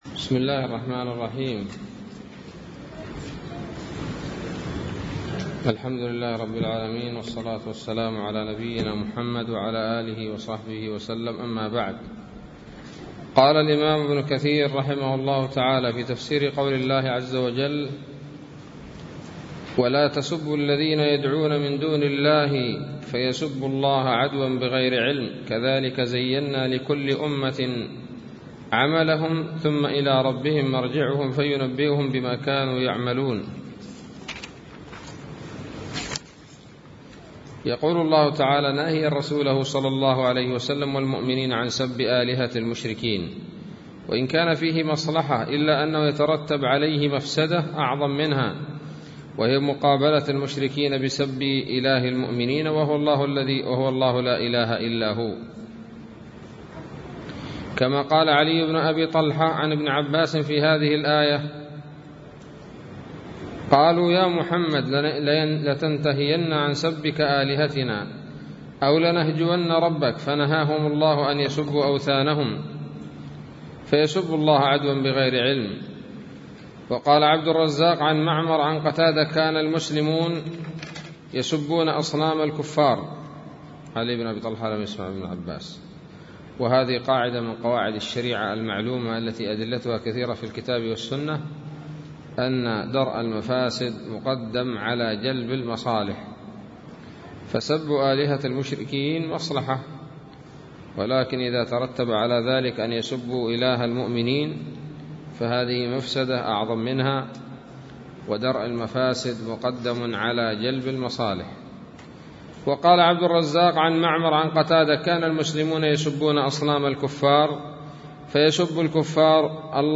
الدرس التاسع والثلاثون من سورة الأنعام من تفسير ابن كثير رحمه الله تعالى